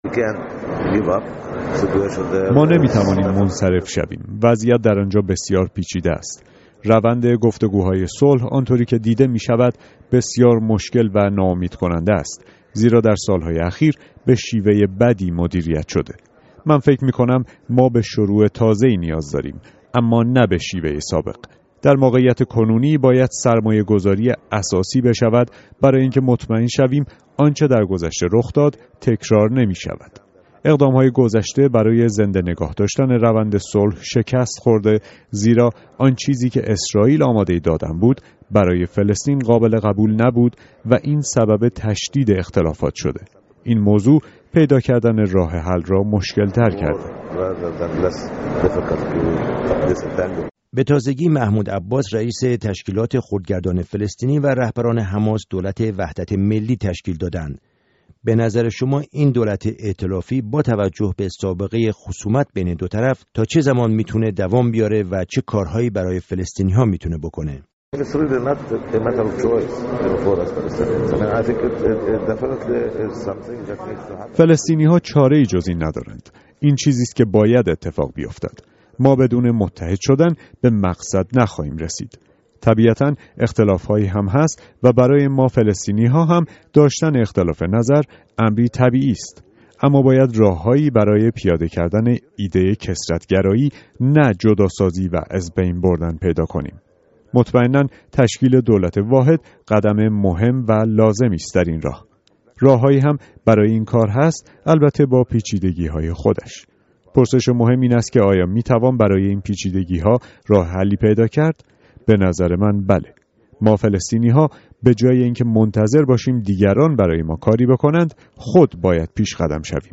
گفت وگوی